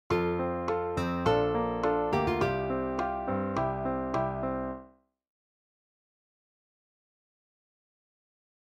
After some experimenting, I’ve decided on F  Bbmaj7  Csus4  C. [